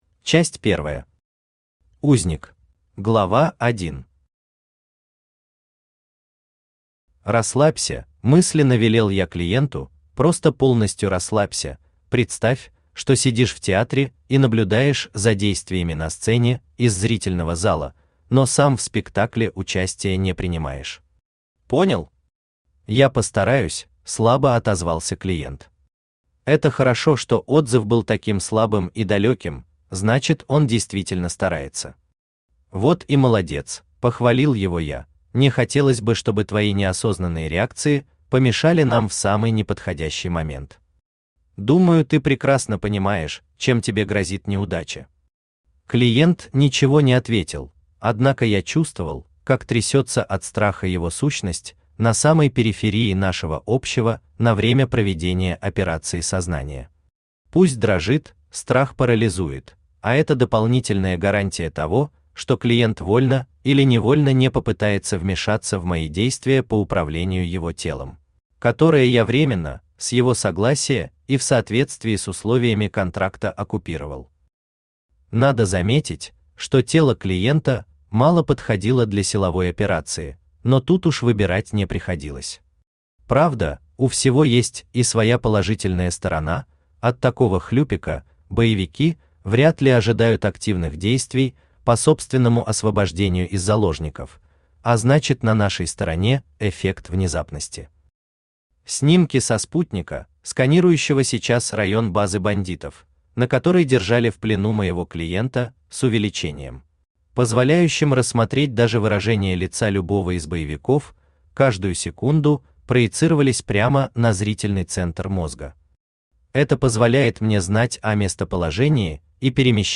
Аудиокнига Оракул прошлого | Библиотека аудиокниг
Aудиокнига Оракул прошлого Автор Леонид Зайцев Читает аудиокнигу Авточтец ЛитРес.